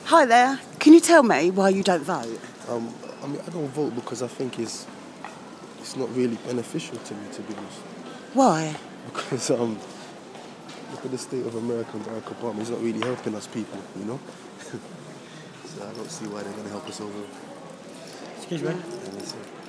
This young man doesn't feel as if there is any point voting